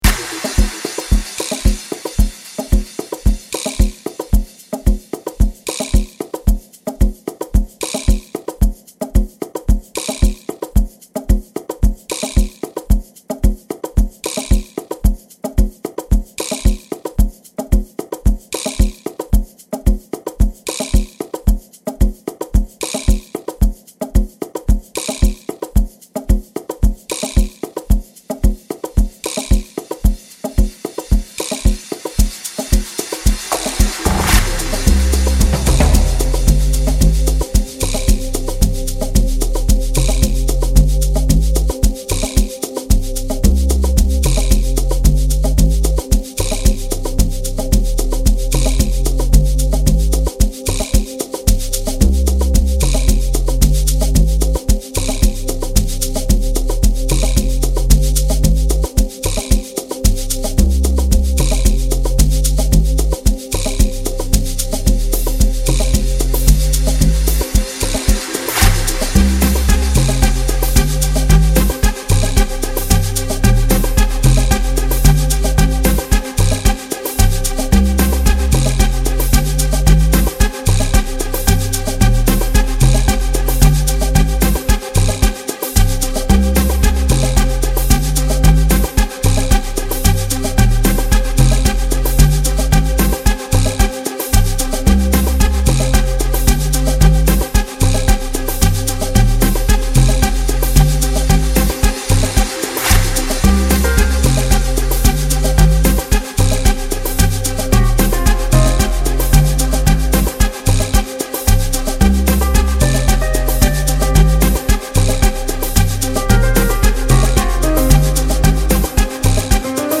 piano duo